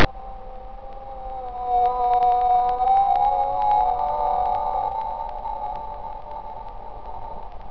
Whalecry
WHALECRY.wav